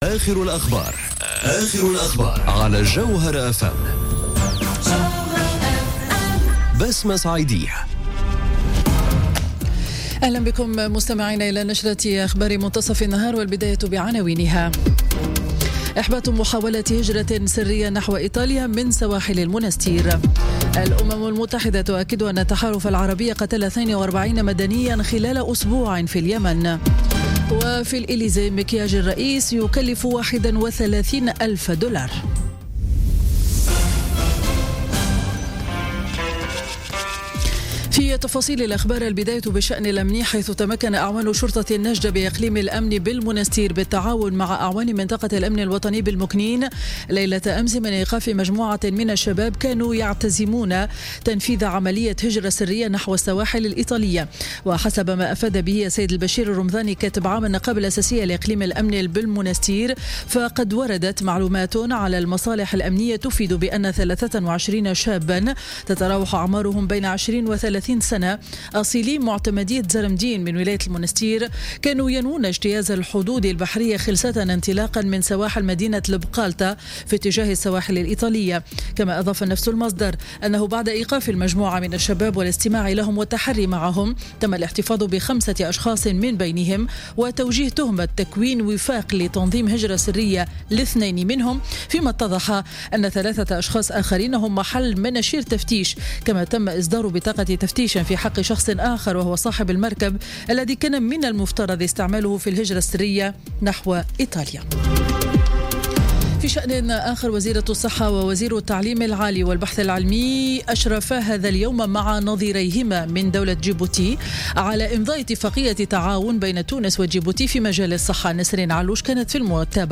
نشرة أخبار منتصف النهار ليوم الجمعة 25 أوت 2017